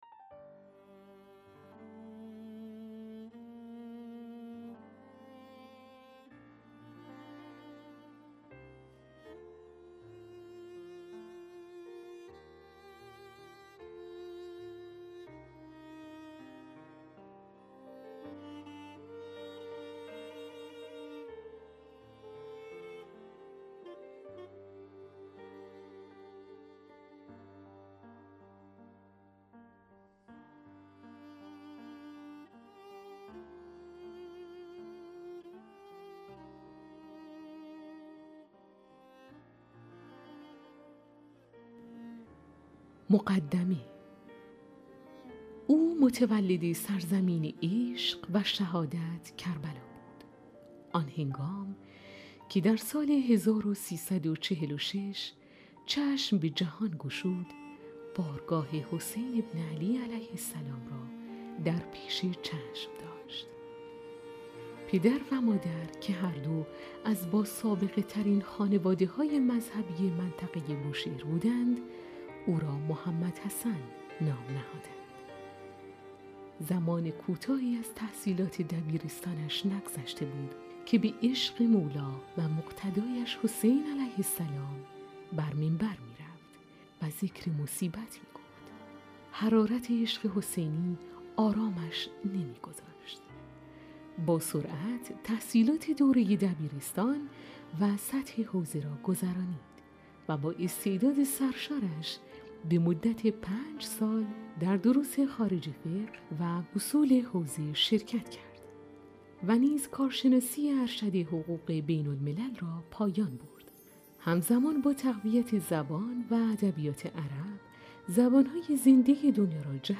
کتاب صوتی/ «ماه گویان» قسمت دوم